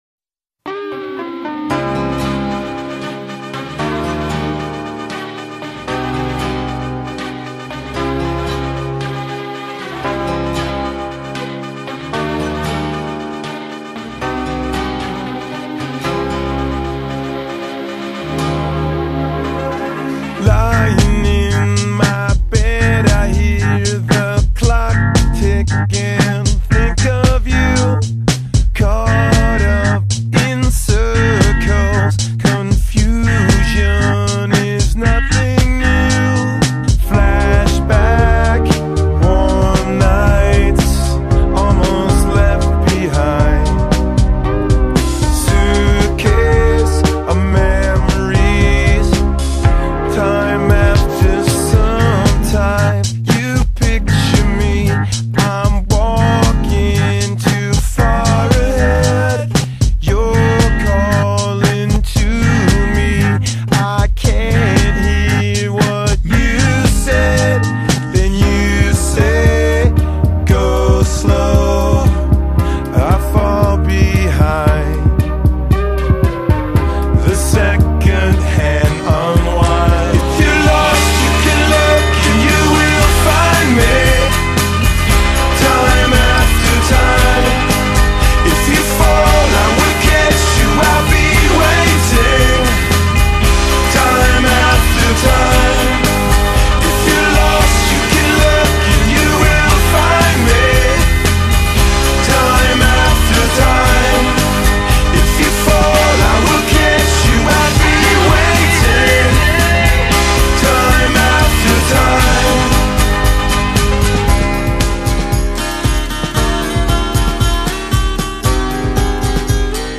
音樂類別：新新搖滾